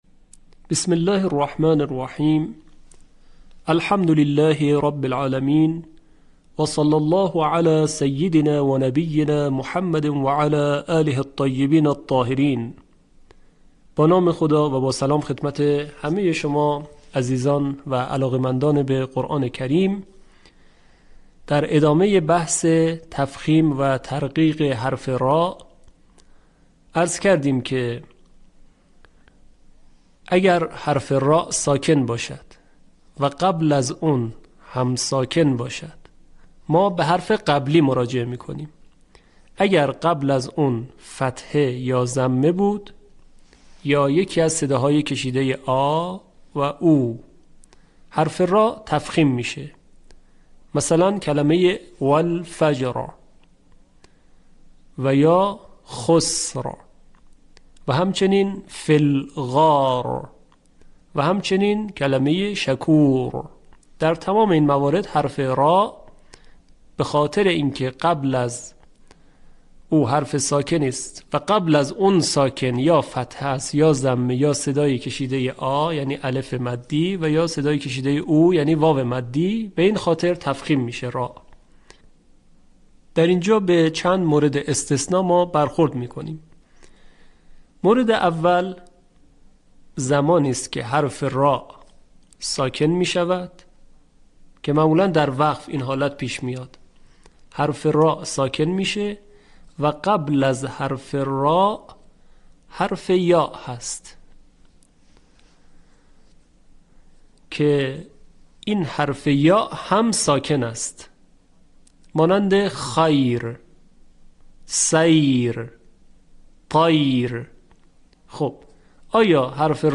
به همین منظور مجموعه آموزشی شنیداری (صوتی) قرآنی را گردآوری و برای علاقه‌مندان بازنشر می‌کند.
آموزش تجوید